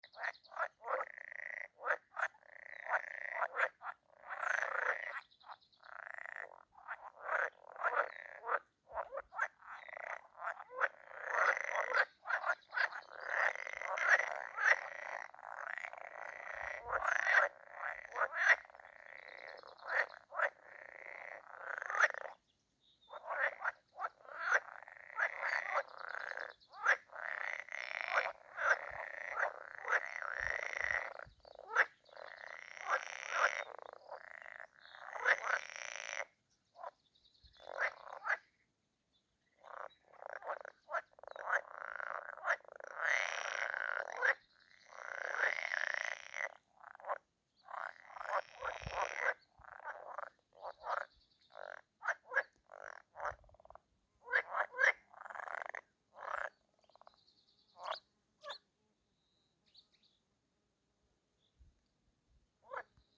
Common tree frog, Hyla arborea
StatusSinging male in breeding season